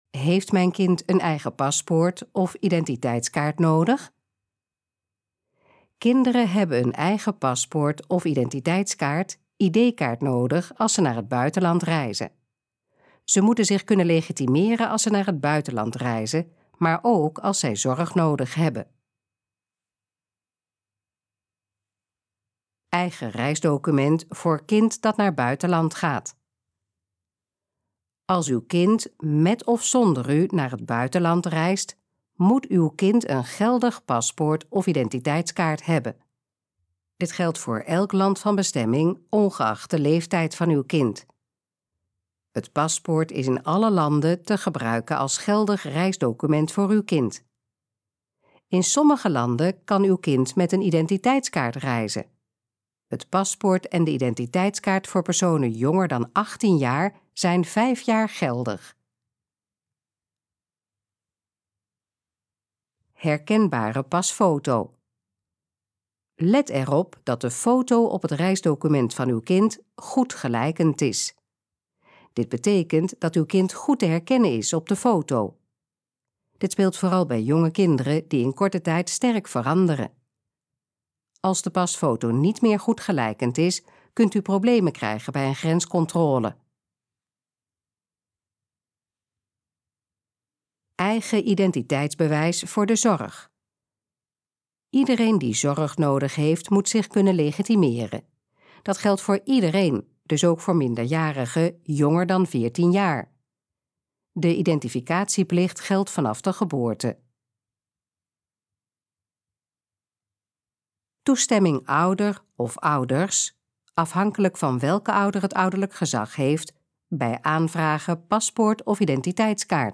Gesproken versie van: Heeft mijn kind een eigen paspoort of identiteitskaart nodig?
Dit geluidsfragment is de gesproken versie van de pagina: Gesproken versie van: Heeft mijn kind een eigen paspoort of identiteitskaart nodig?